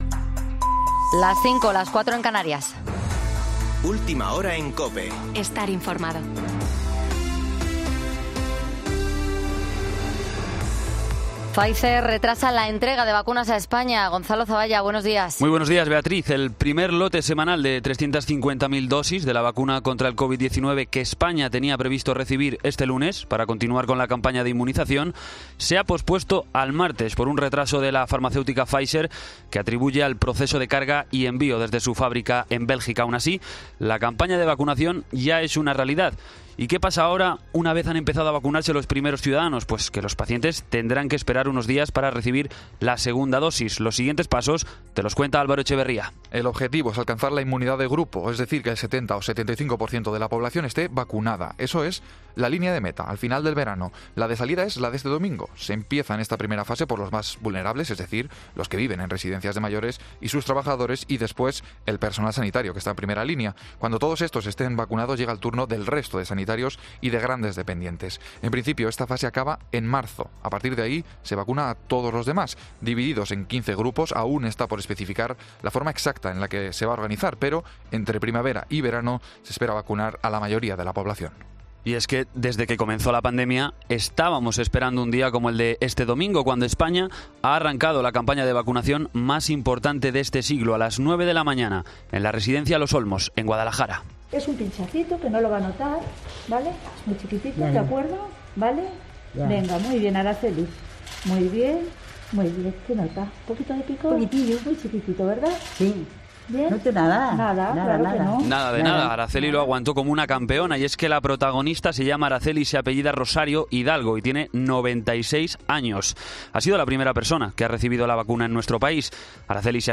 Boletín de noticias COPE del 28 de diciembre de 2020 a las 05.00 horas